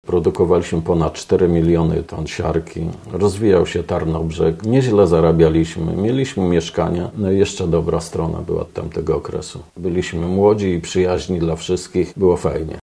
W każdy czwartek po godzinie 12.00, na antenie Radia Leliwa można wysłuchać rozmów z pracownikami przemysłu siarkowego.